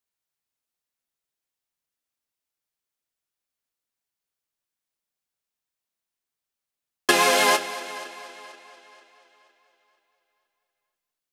VDE 127BPM Rebound Chord Root F.wav